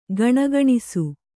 ♪ gaṇagaṇisu